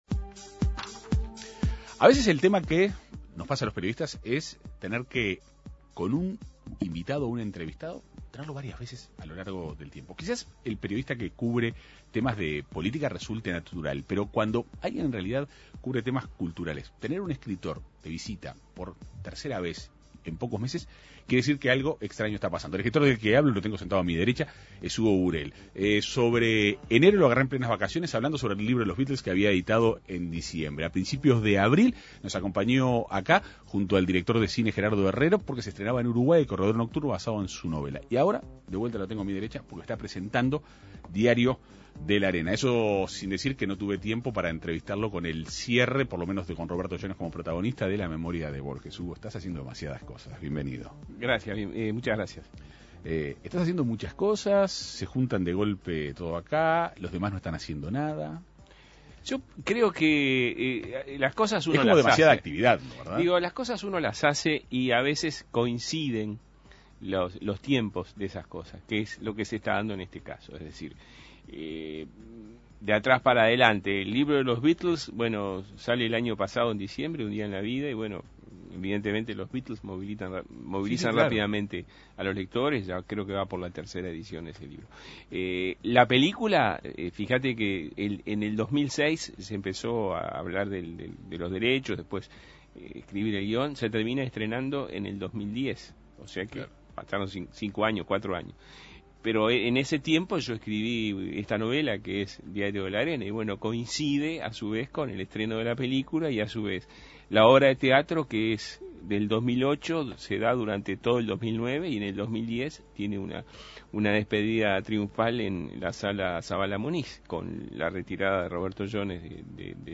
En la soledad del nuevo lugar, en las costas del mar, se narra la historia del personaje protagonista de Diario de arena, la nueva novela de Hugo Burel. El autor dialogó en la Segunda Mañana de En Perspectiva.